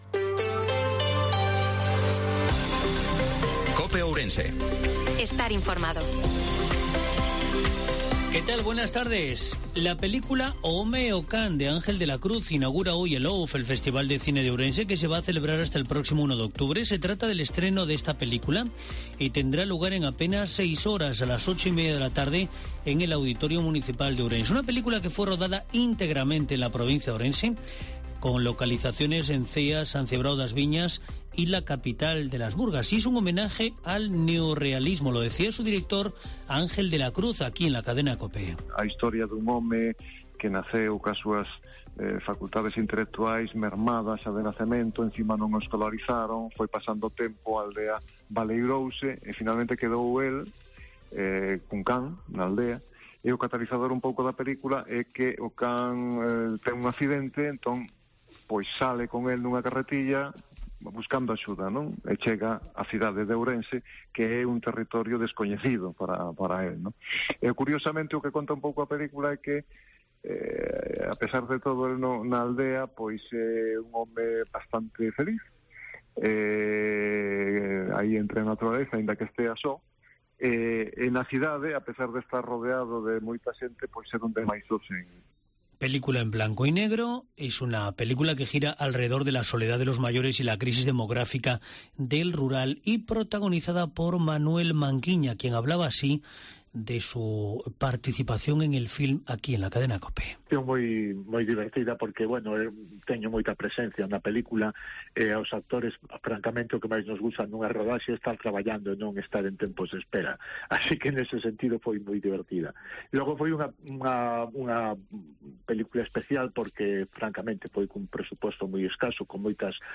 INFORMATIVO MEDIODIA COPE OURENSE-23/09/2022